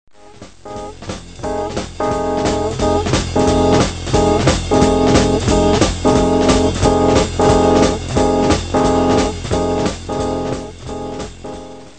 42_escapeSound.mp3